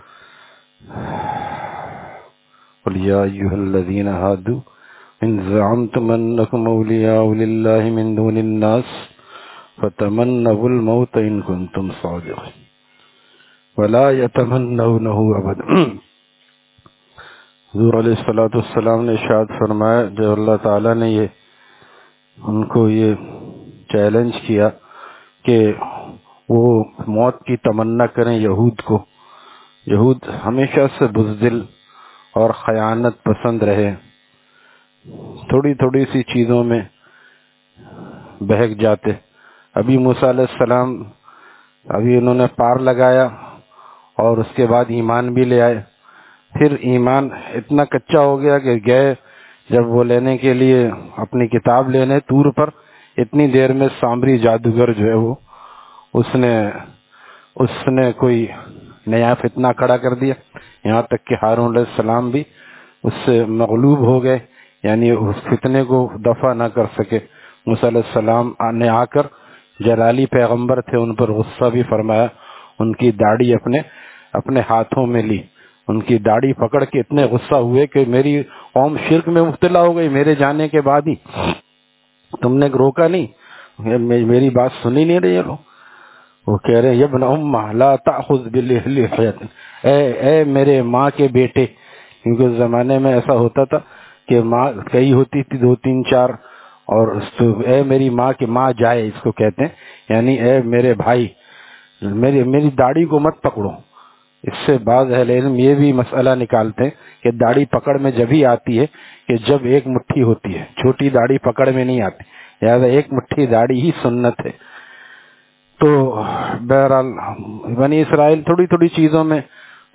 Taleem After Fajar at Jama Masjid Gulzar e Muhammadi, Khanqah Gulzar e Akhter, Sec 4D, Surjani Town